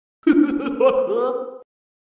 На этой странице собрана коллекция звуков и голосовых фраз Haggy Wagy.
Смех Хаги Ваги